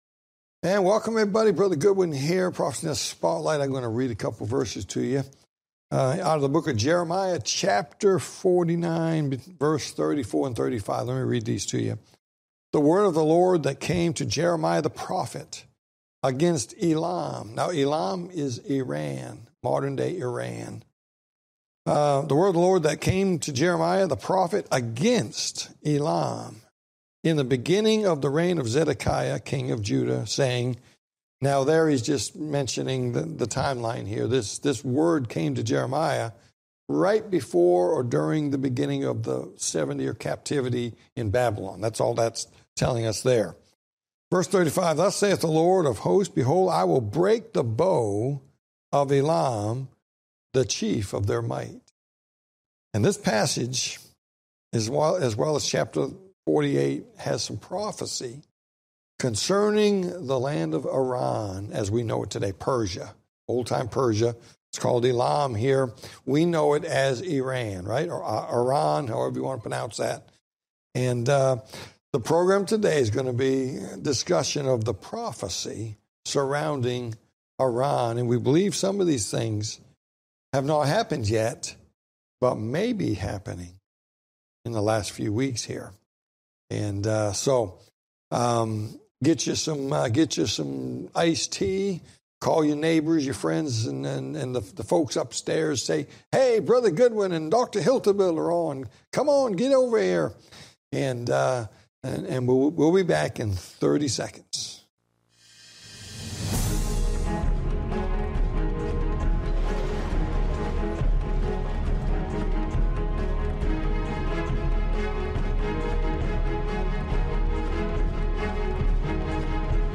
Talk Show Episode, Audio Podcast, Prophecy In The Spotlight and Iran And Prophecy Pt-1, And Second Coming Of Moses Pt-2 on , show guests , about Iran And Prophecy,Second Coming Of Moses, categorized as History,News,Politics & Government,Religion,Society and Culture,Theory & Conspiracy